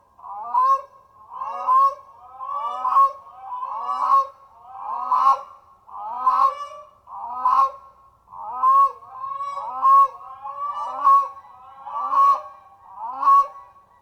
Grey Crowned Crane
Unison Call | A duet performed by a pair, to strengthen their bond and protect their territory.
Grey-Crowned-Crane-Unison-Call.mp3